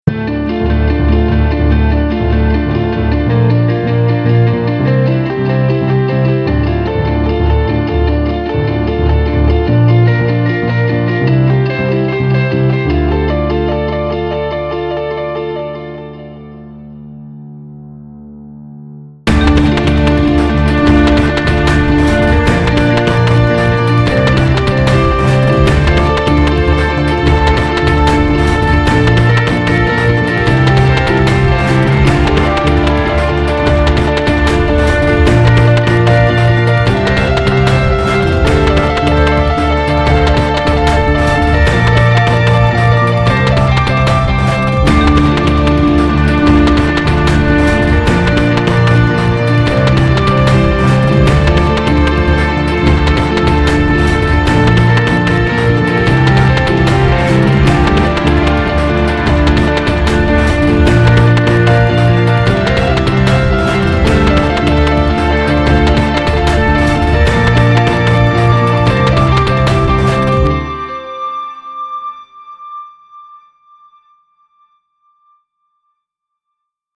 I just got Guitar Rig 4 and I wanted to try out some new effects. The atmosphere is kinda like a city atmosphere.